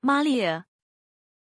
Prononciation de Maélia
pronunciation-maélia-zh.mp3